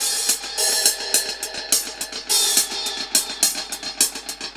Index of /musicradar/dub-drums-samples/105bpm
Db_DrumsA_HatsEcho_105_02.wav